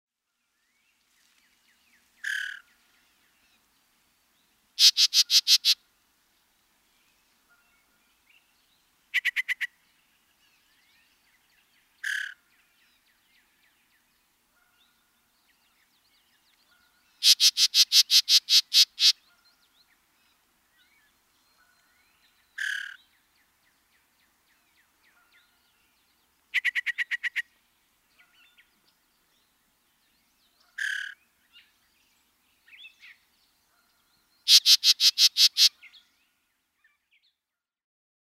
Yellow-breasted Chat
BIRD CALL: LONG, VARIABLE SERIES OF WHISTLES, SQUIRREL-LIKE CHATTERS, AND HIGH-PITCHED CALLS.
Yellow-breasted-chat-call.mp3